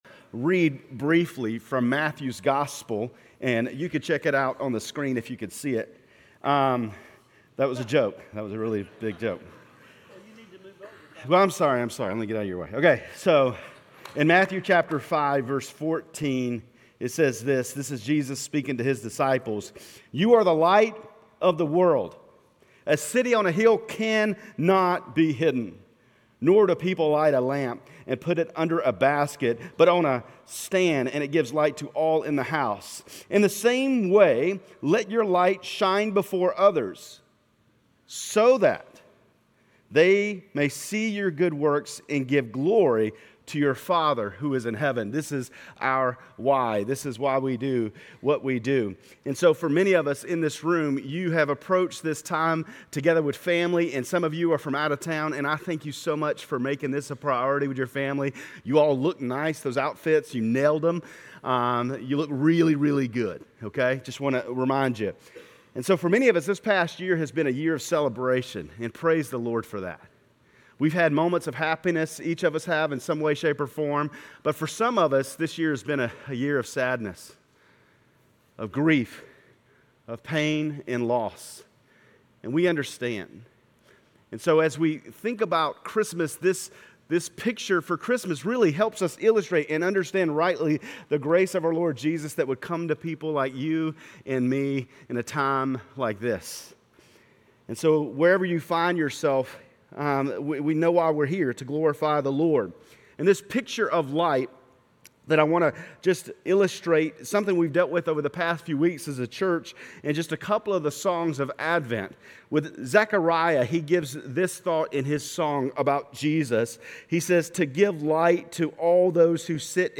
Grace Community Church Lindale Campus Sermons Christmas Eve Dec 24 2023 | 00:09:28 1x 00:00 / 00:09:28 Subscribe Share RSS Feed Share Link Embed